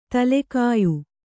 ♪ tale kāyu